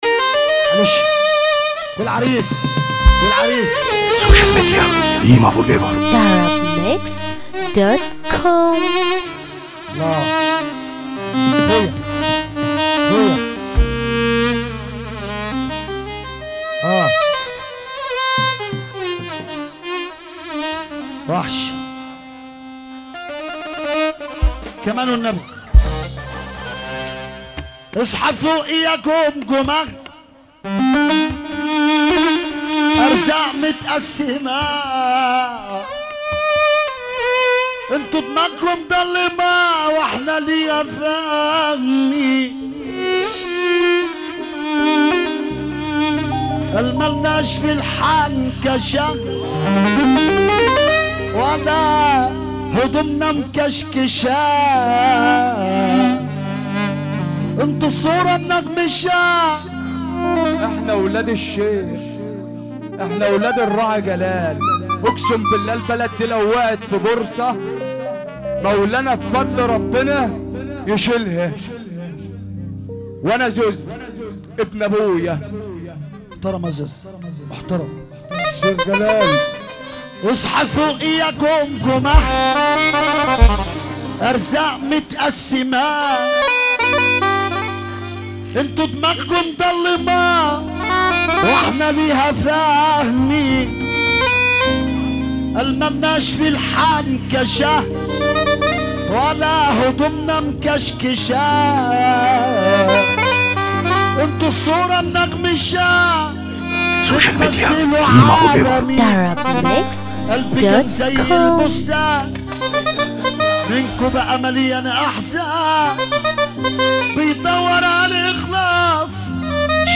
موال
حزينة جد